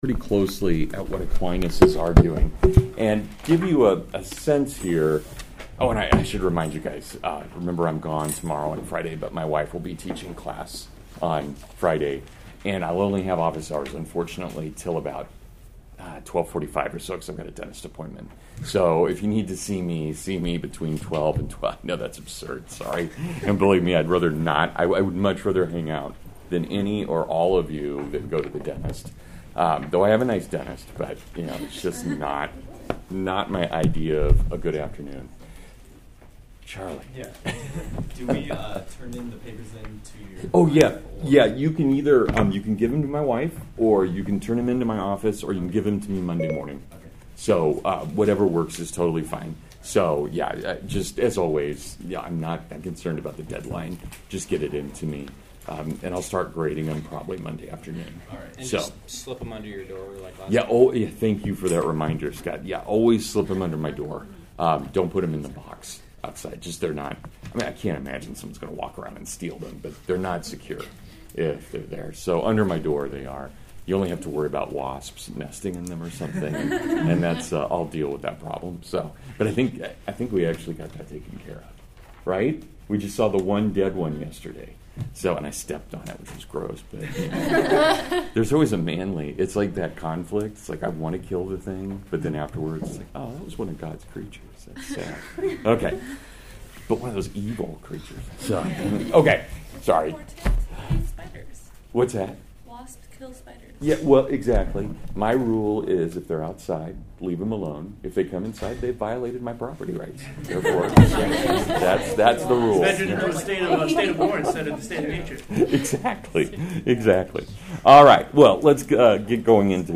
Thomas Aquinas (Full Lecture)